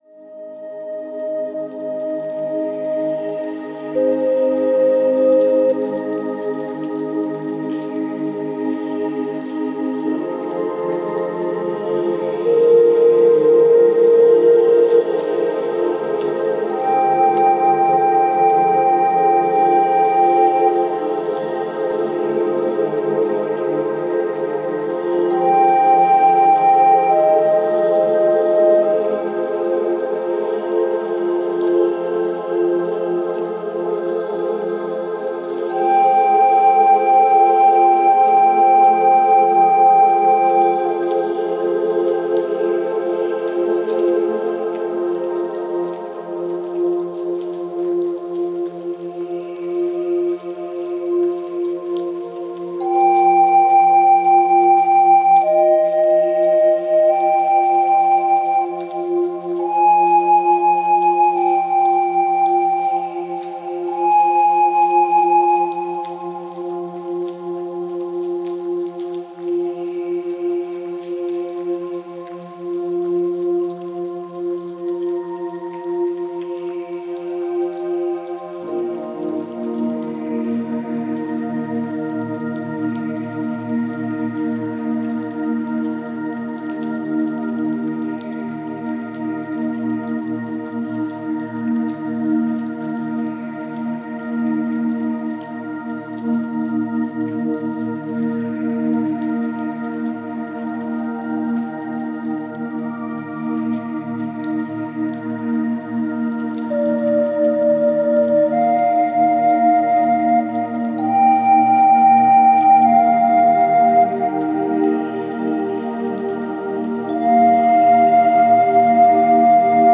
Музыка природы 0 491 1 Добавлено в плейлист